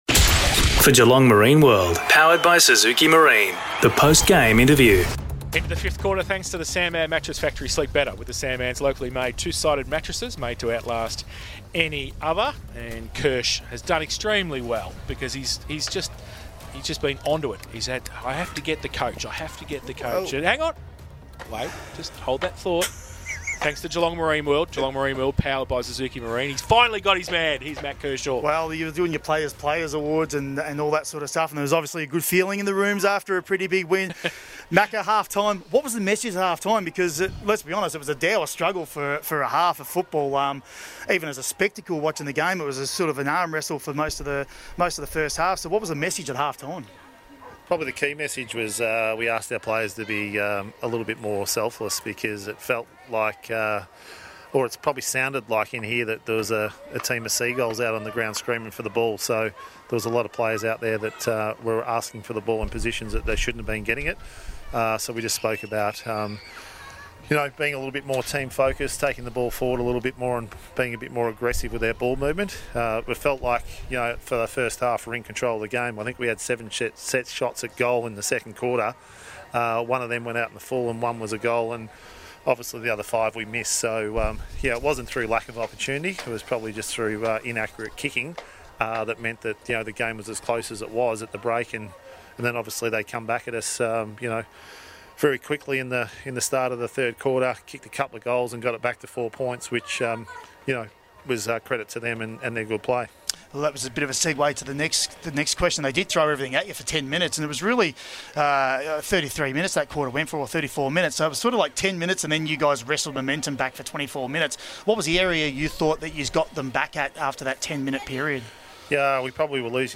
2022 – GFL ROUND 14 – NEWTOWN & CHILWELL vs. GEELONG WEST: Post-match Interview